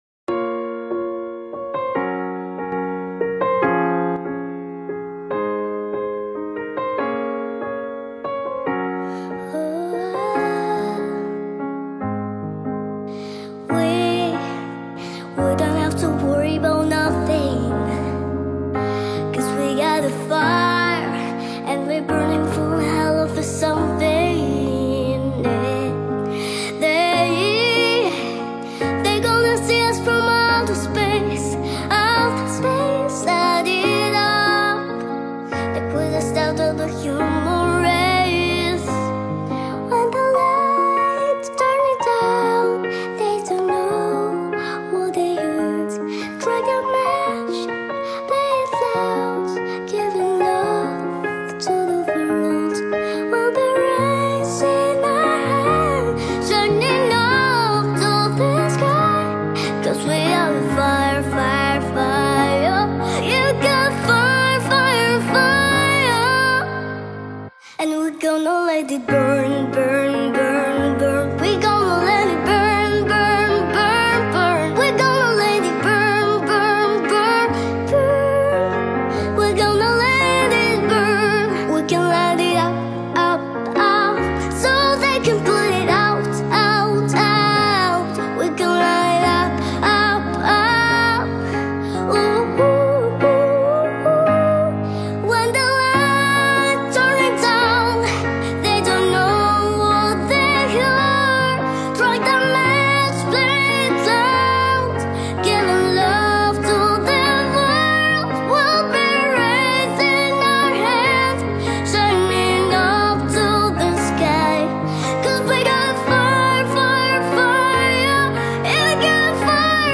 Вокальный конкурс Леонида Агутина